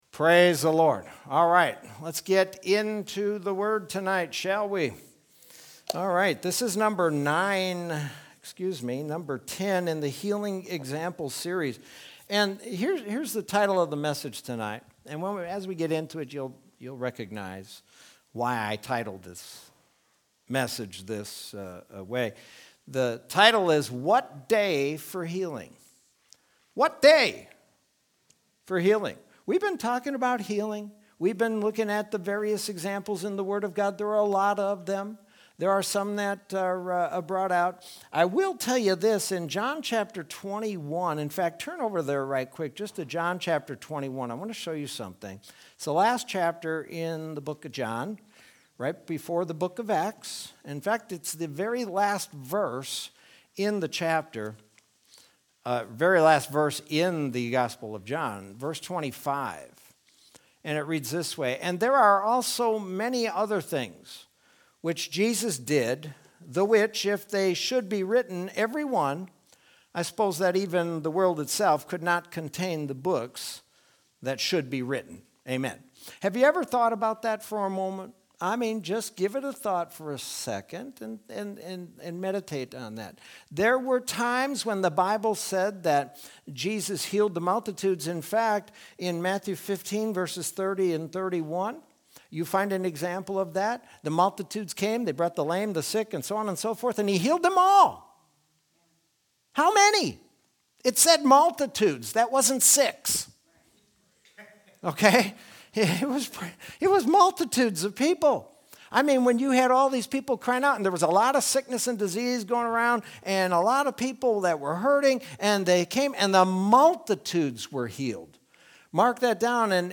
Sermon from Wednesday, March 24th, 2021.